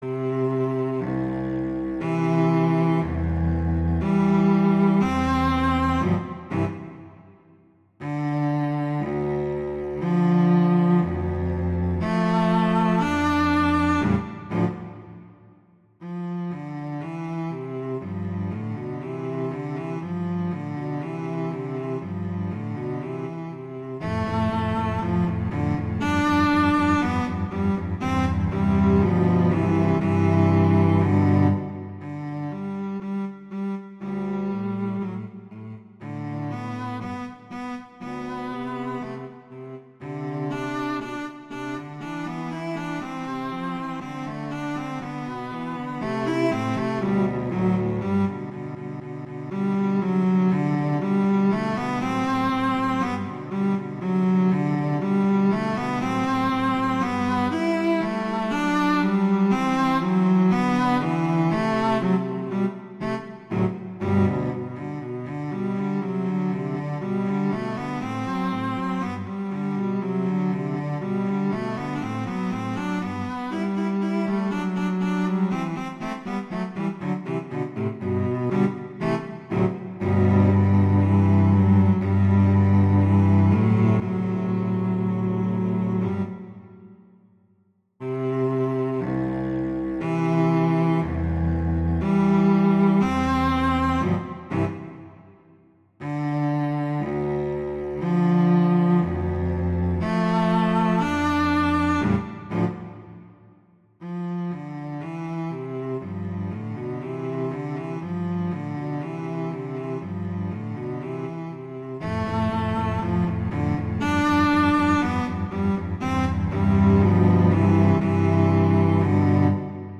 classical, french, children